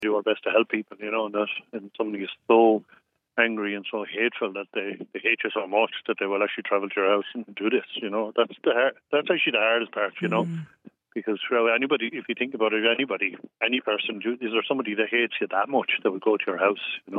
Speaking on Kildare Today, Cllr Clear said he believes it was a targeted attack, after his address was made public as part of his general election bid.